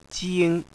jing1.wav